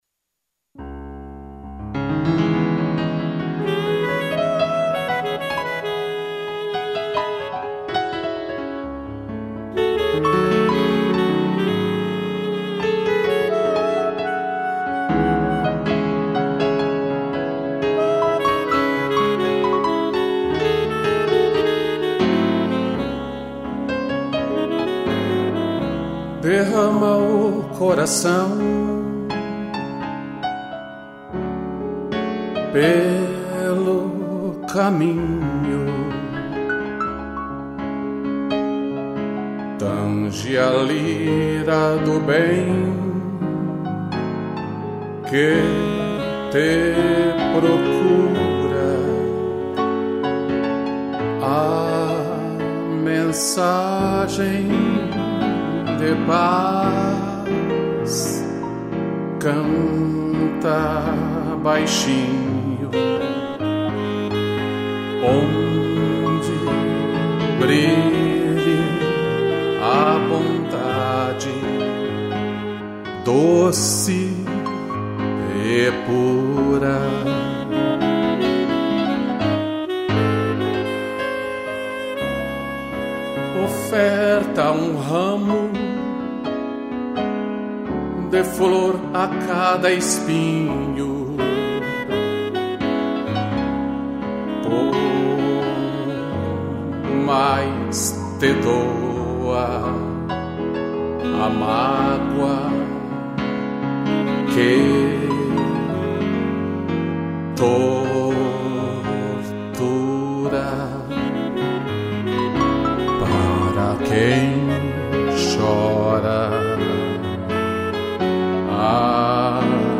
voz
2 pianos e sax